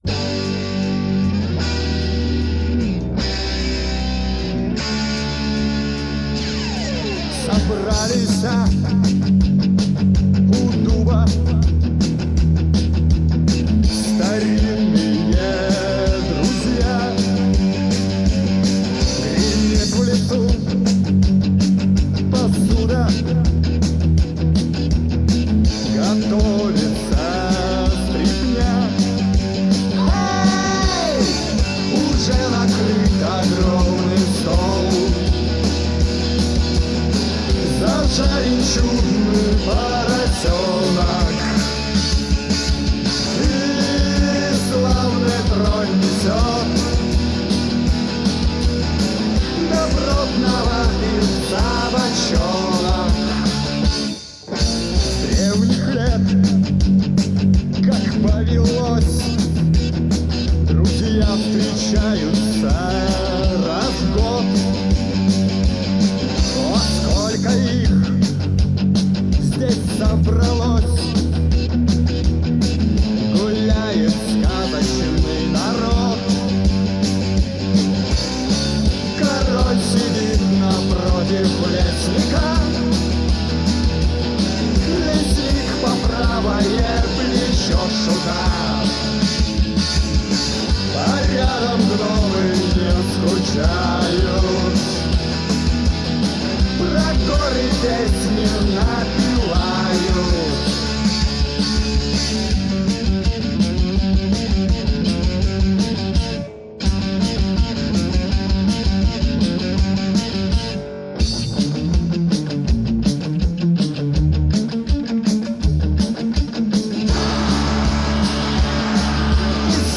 Жанр: Punk